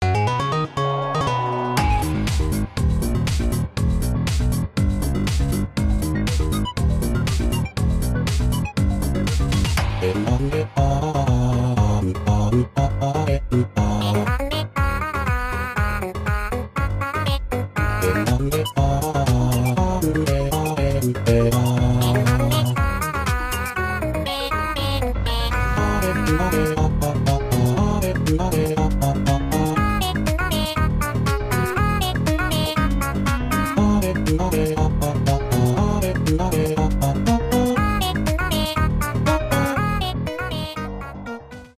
электронные